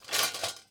SFX_Cooking_Utensils_02.wav